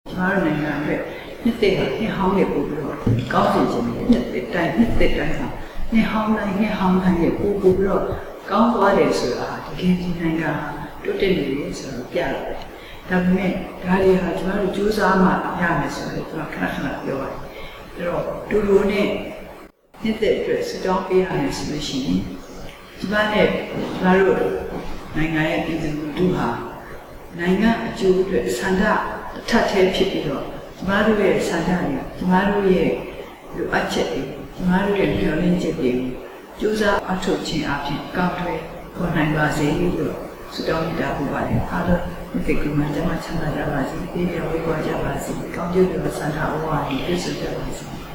အမျိုးသားဒီမိုကရေစီအဖွဲ့ချုပ် ဥက္ကဌ ဒေါ်အောင်ဆန်းစုကြည်ကတော့ ဒီနှစ်သစ်မှာ တိုင်းပြည်အကျိုးအတွက် ပြည်သူ အားလုံးပါဝင် ကြိုးပမ်းအားထုတ်ကြဖို့ NLD ဖေ့စ်ဘုတ်စာမျက်နှာကနေ အခုလို နှိုးဆော်စကား ပြောကြားခဲ့ပါတယ်။